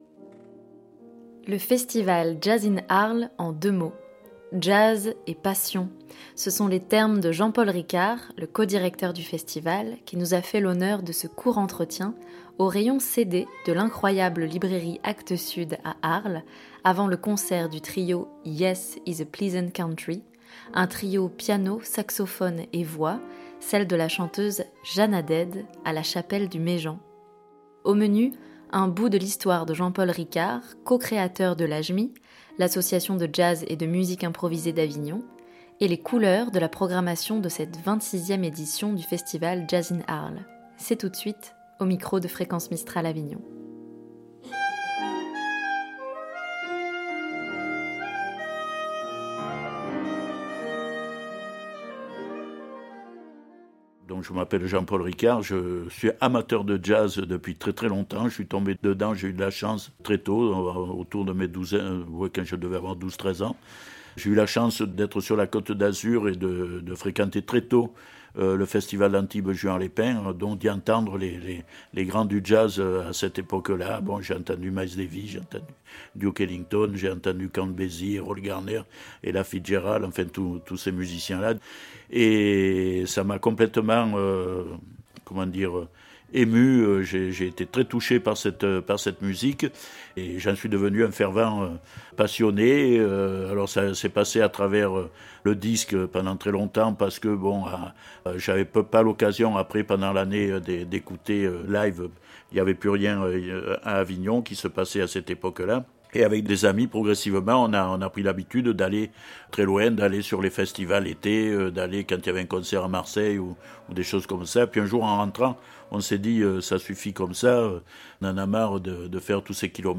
C’est tout de suite, au micro de Fréquence Mistral Avignon.